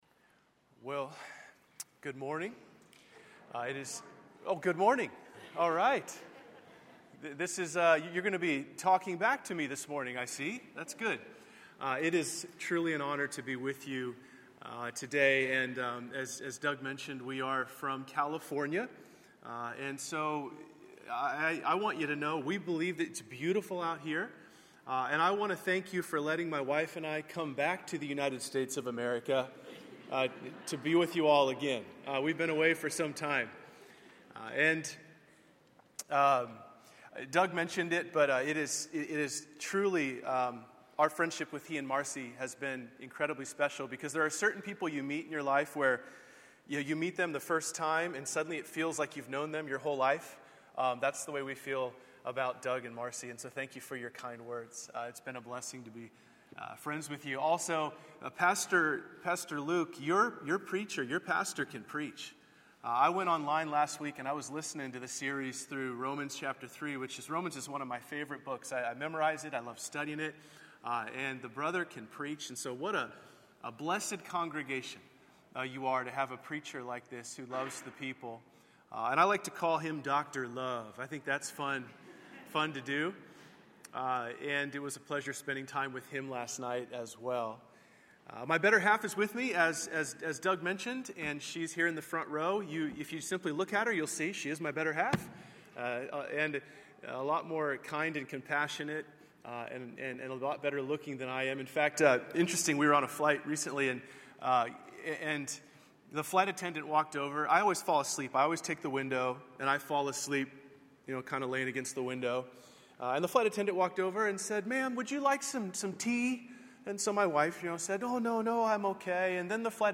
A sermon from the series "Individual Sermons."